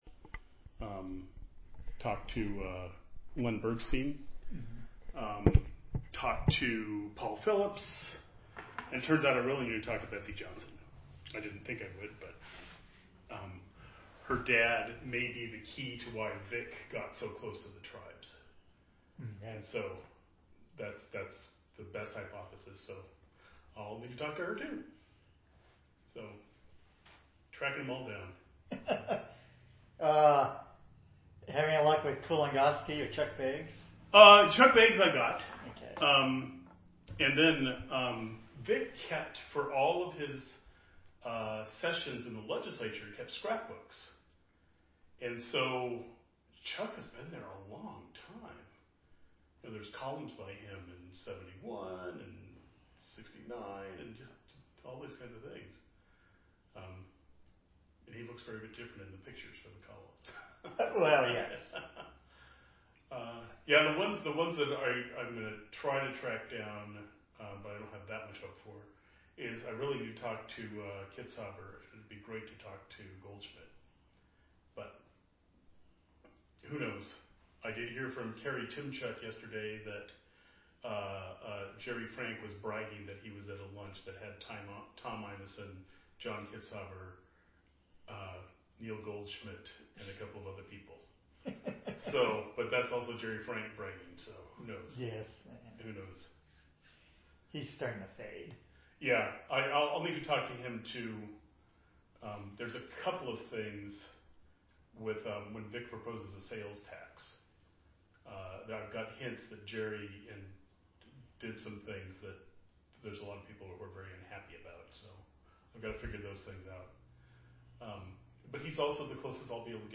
Description An interview